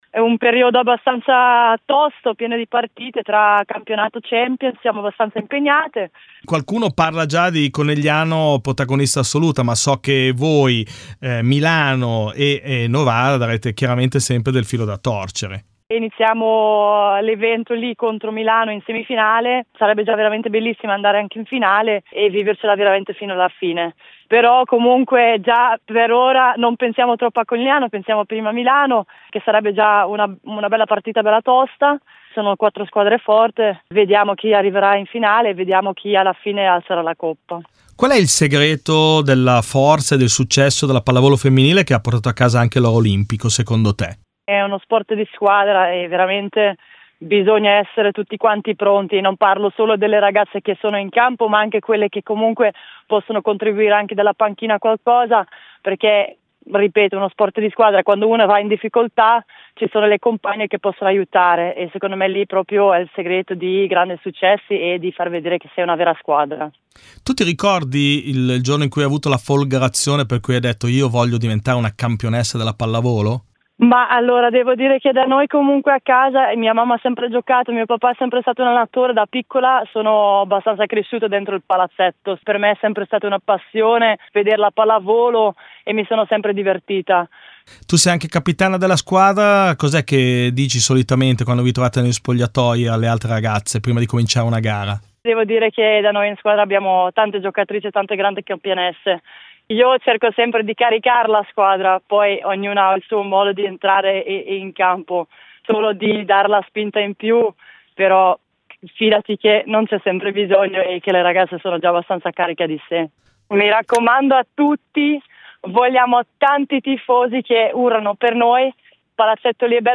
Ai microfoni di Radio Bruno le voci di alcune delle protagoniste delle squadre che scenderanno in campo per la vittoria finale.